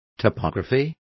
Complete with pronunciation of the translation of topography.